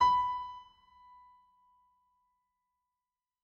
SoftPiano
b4.mp3